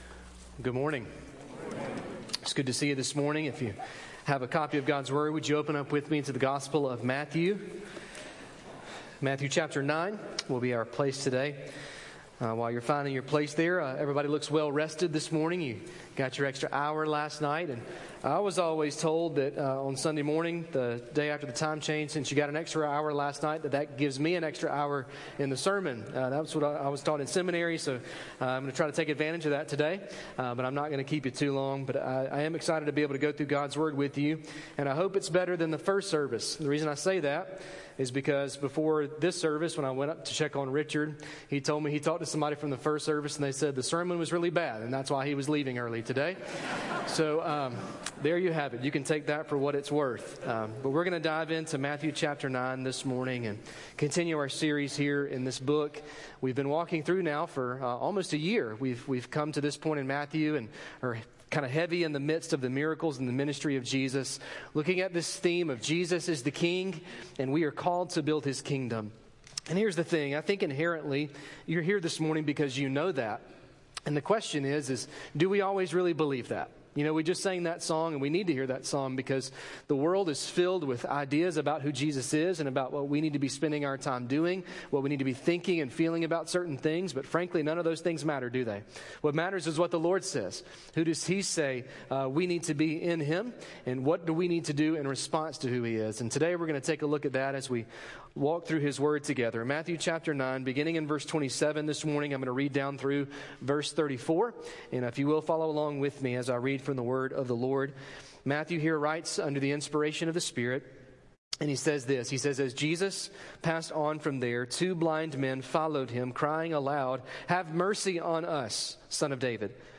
Message
Sermon